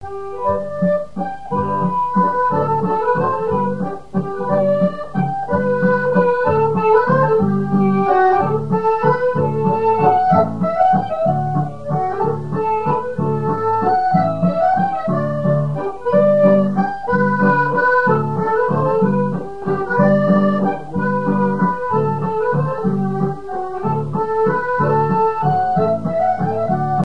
Thème : 1074 - Chants brefs - A danser
Fonction d'après l'analyste danse : mazurka
Catégorie Pièce musicale inédite